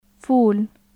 下唇に上の歯を軽く当てた隙間に，勢いよく呼気を通して発音される/f/の音です。 /f/ 無声・唇歯・摩擦音/f/ ف /faːʔ/ f （アルファベット） فاكهة /faːkiha/ 果物 سفارة /sifaːra/ 大使館 في /fiː/ ～の中 فلم /film/ 映画，フィルム فول /fuːl/ そら豆 رفوف /rufuːf/ 棚 （複数形） دفتر /daftar/ ノート رف /raff/ 棚